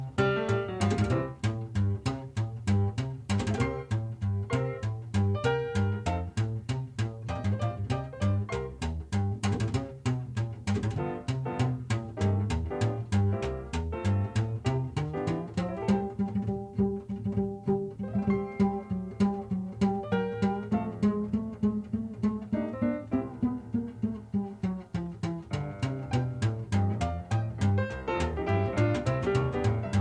"enPreferredTerm" => "Jazz"